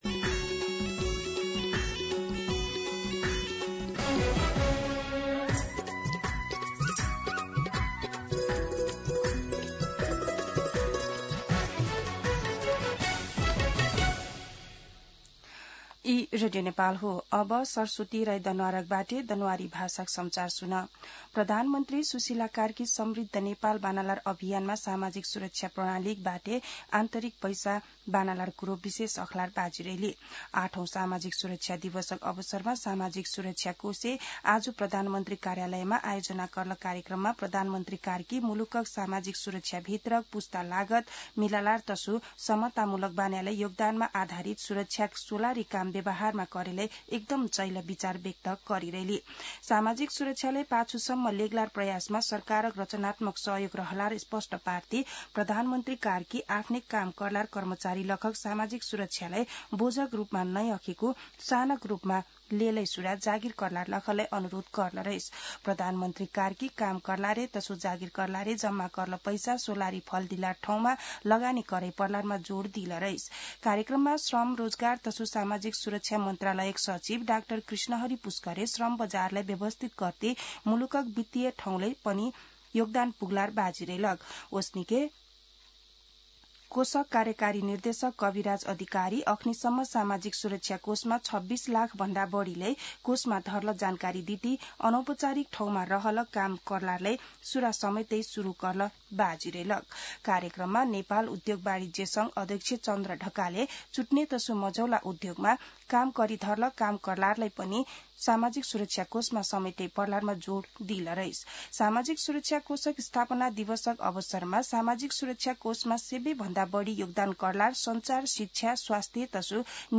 दनुवार भाषामा समाचार : ११ मंसिर , २०८२
Danuwar-News-8-11.mp3